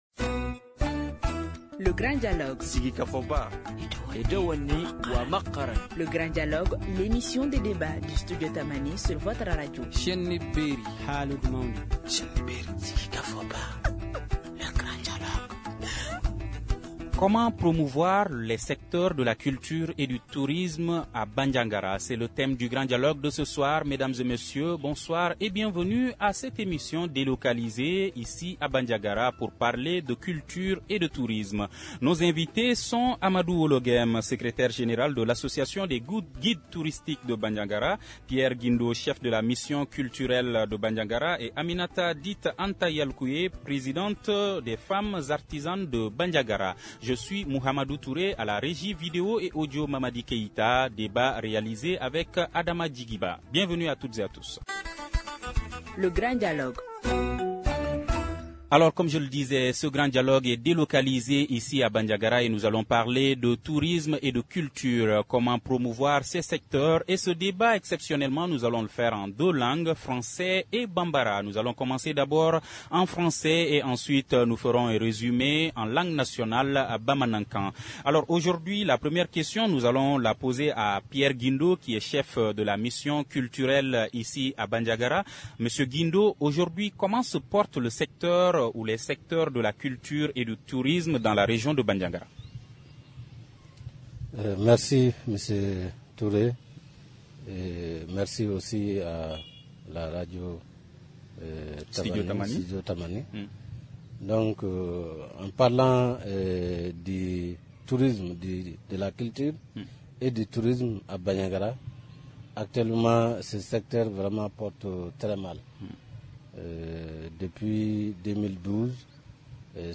Ce Grand Dialogue de Studio Tamani est délocalisé à Bandiagara, au centre du Mali, pour parler des défis auquels les secteurs de la culture et du tourisme sont confrontés dans cette région frappée par l’insécurité.
Studio Tamani pose le débat.